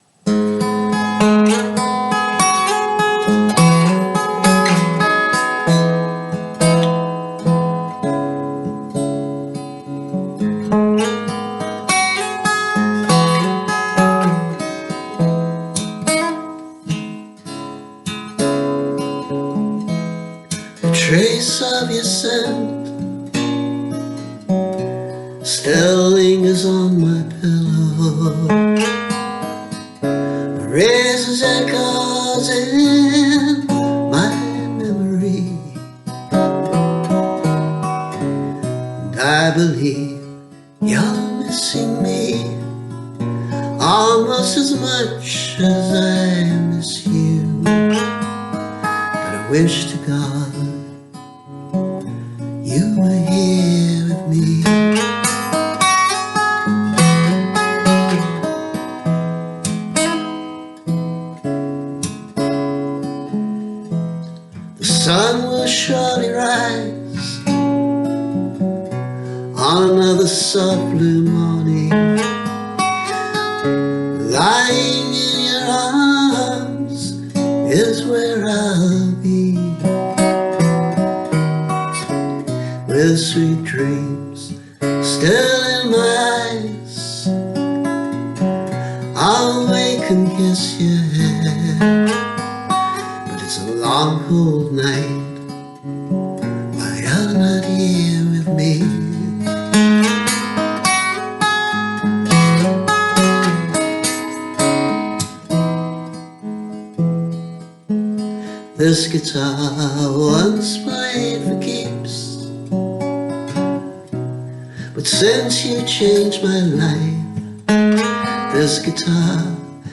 There’s a less country-ish version here, recorded more recently.